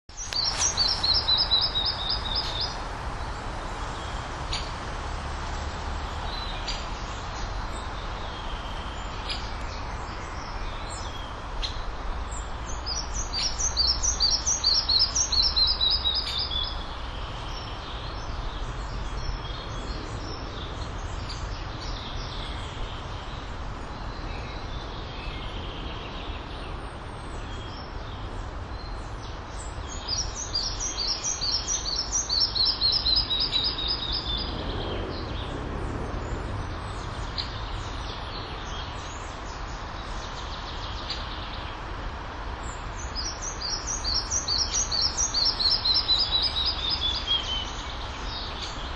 Mazais mušķērājs, Ficedula parva
Administratīvā teritorijaRīga
StatussDzied ligzdošanai piemērotā biotopā (D)